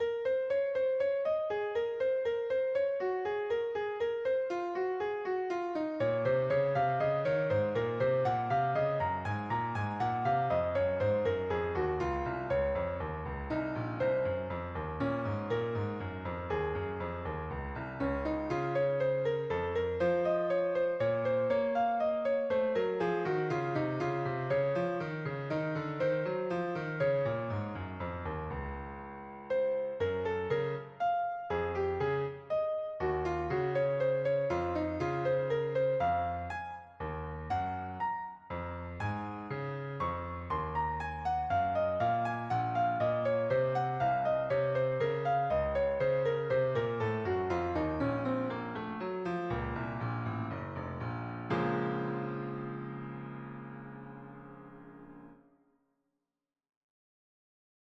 Invention In B Flat Minor